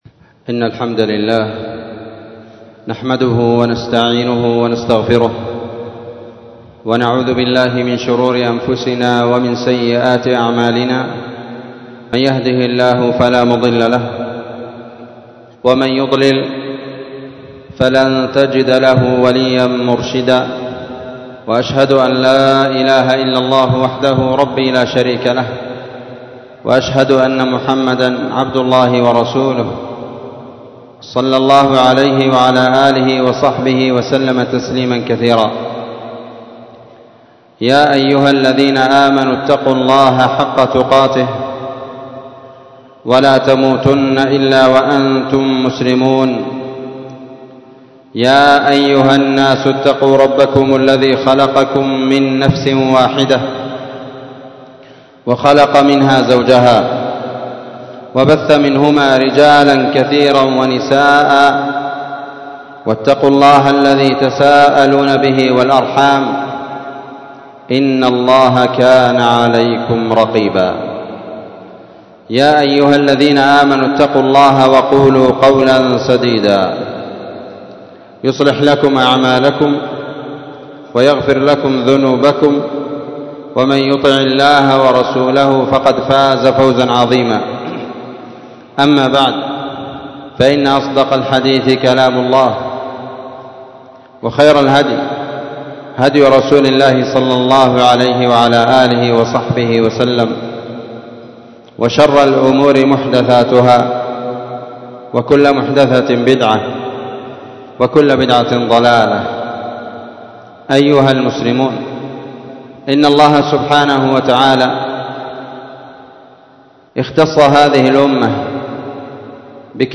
محاضرة بعنوان نور العينين ببيان إرث الوحيين، ليلة 19 شعبان 1444
مسجد المجاهد- النسيرية- تعز